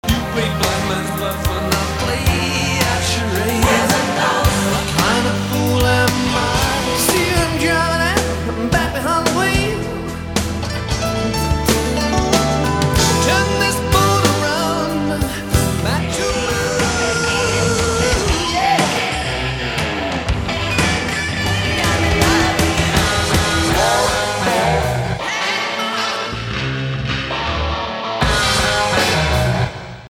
Lighten up, baby, it’s a sampler mix: